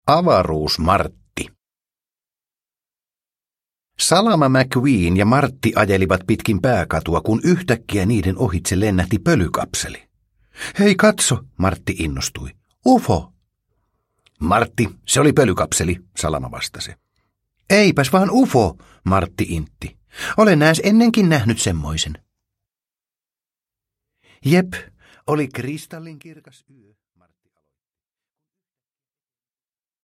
Pixar Autot. Avaruus-Martti – Ljudbok – Laddas ner